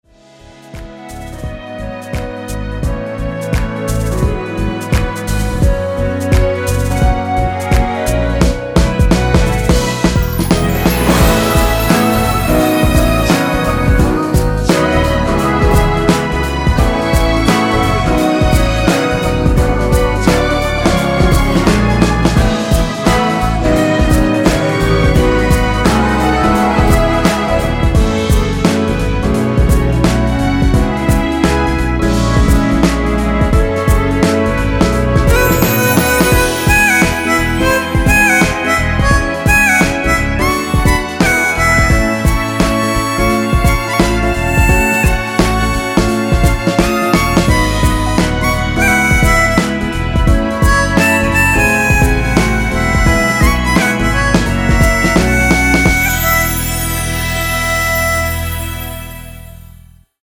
엔딩이 페이드 아웃이라서 노래하기 편하게 엔딩을 만들어 놓았으니 미리듣기 확인하여주세요!
원키에서(-4)내린 멜로디와 코러스 포함된 MR입니다.
앞부분30초, 뒷부분30초씩 편집해서 올려 드리고 있습니다.